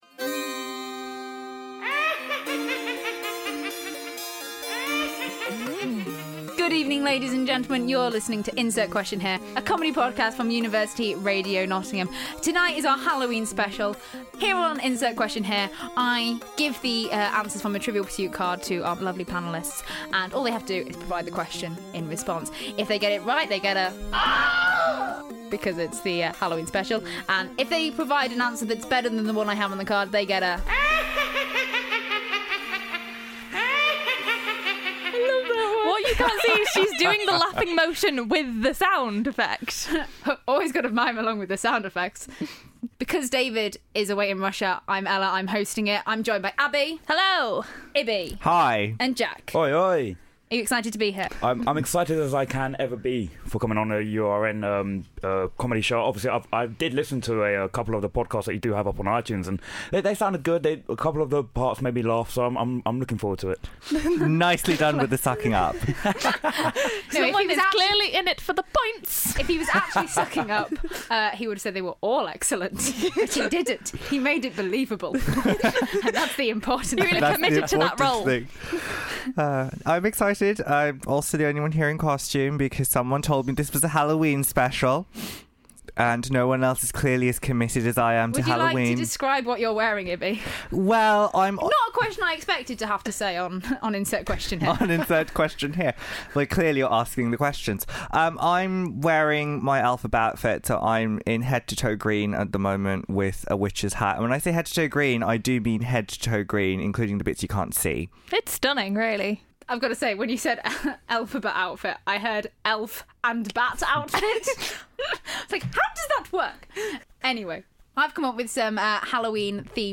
4 students (but three new ones). 1 pack of children's Trivial Pursuit cards (actually trivia questions off the internet this week).